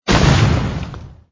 leiting.mp3